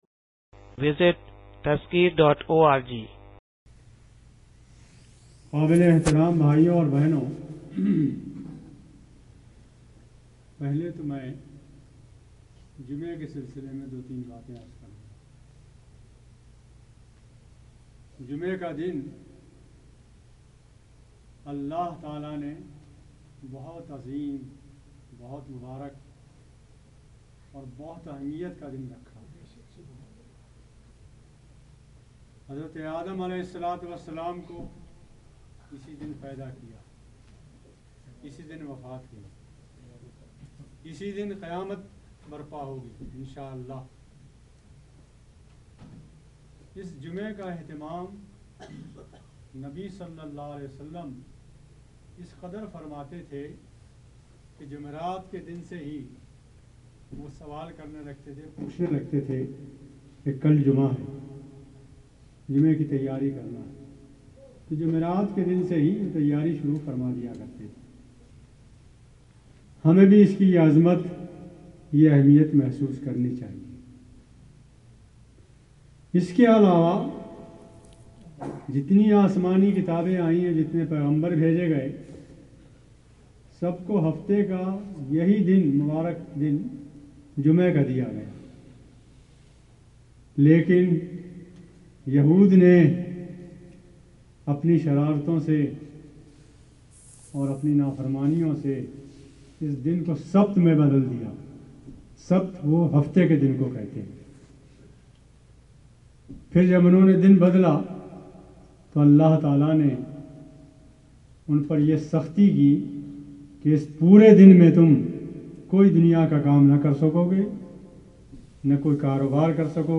Friday Lecture 2009